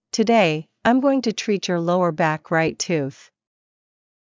ﾄｩﾃﾞｲ ｱｲﾑ ｺﾞｰｲﾝｸﾞ ﾄｩｰ ﾄﾘｰﾄ ﾕｱ ﾛｳｱｰ ﾊﾞｯｸ ﾗｲﾄ ﾄｩｰｽ